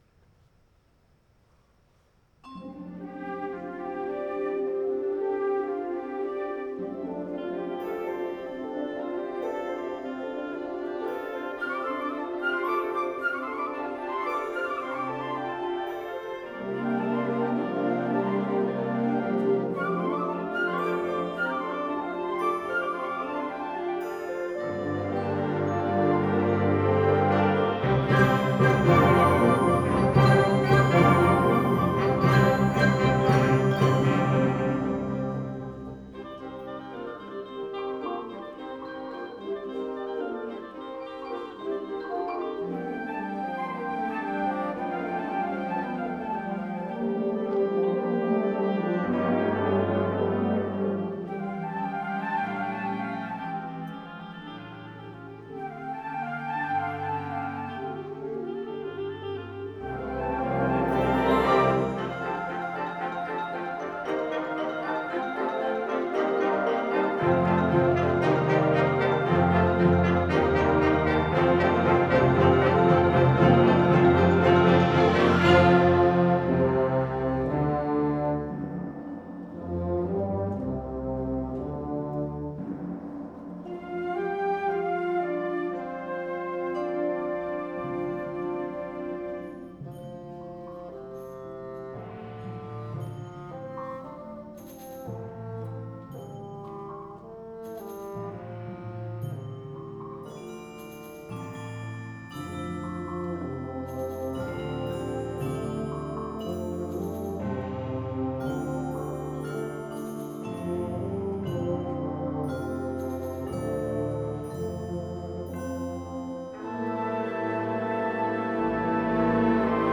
2024 UIL Symphonic Winds @ Centennial HS 04.03.24